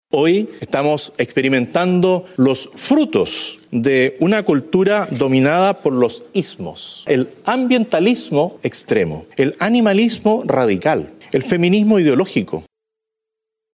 El presidente electo, José Antonio Kast, protagonizó en Bruselas -en el marco de la VII Cumbre Transatlántica por la Libertad de Expresión- uno de sus discursos más identitarios desde su triunfo electoral, marcando con fuerza un tono ideológico que había evitado en los últimos meses, arremetiendo contra el feminismo, el animalismo y el ambientalismo, reivindicando además la figura del exsenador Jaime Guzmán como referente político y doctrinario.